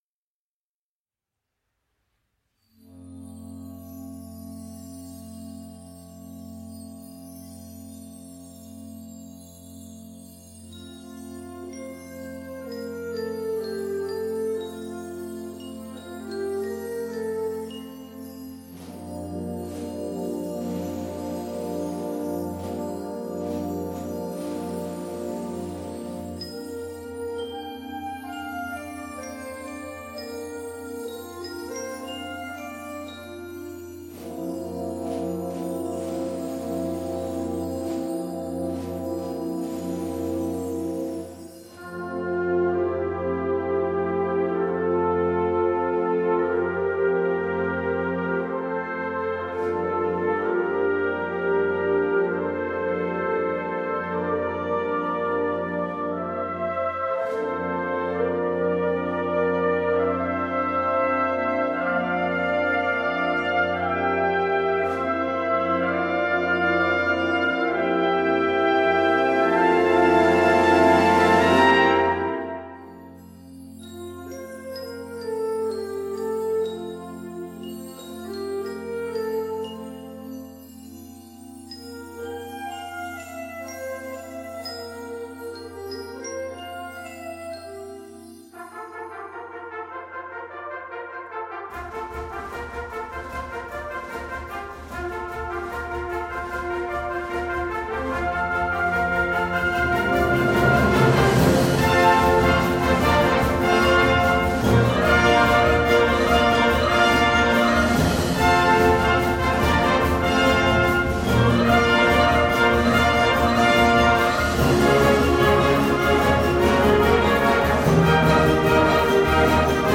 Partitions pour orchestre d'harmonie.